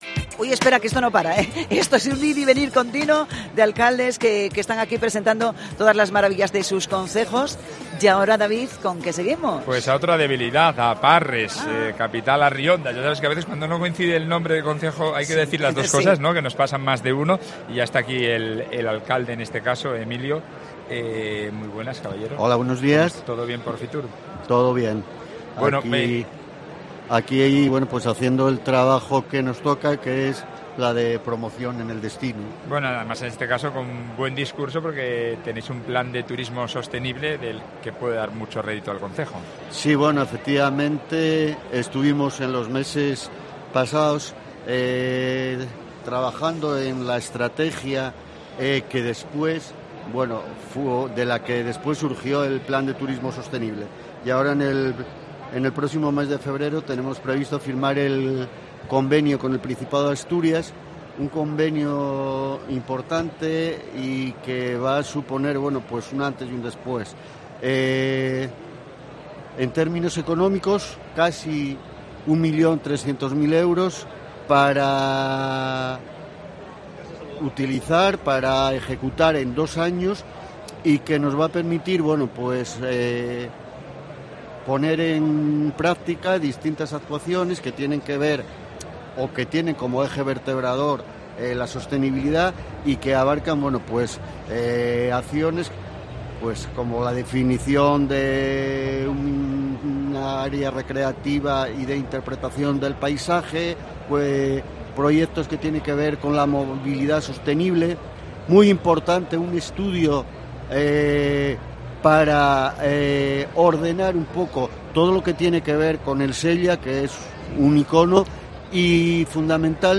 FITUR 2024: Entrevista a Emilio García Longo, alcalde de Parres